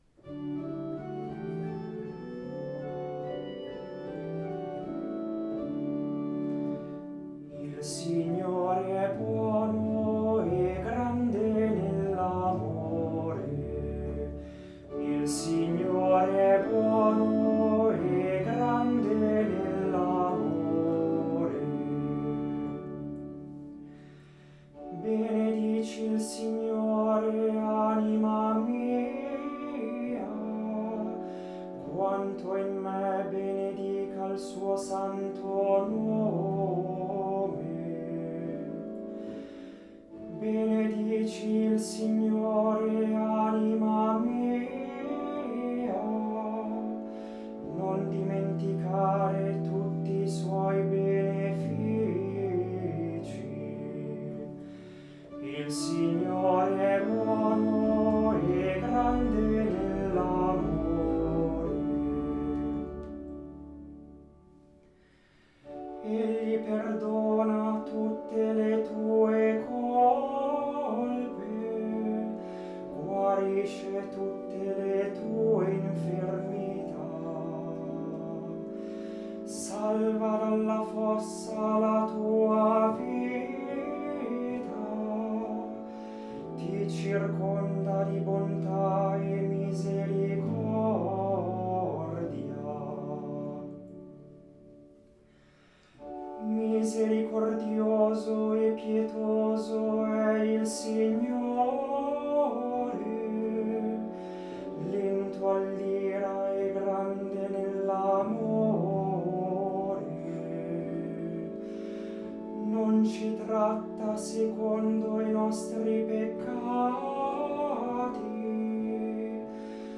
Audio esecuzione a cura degli animatori musicali del Duomo di Milano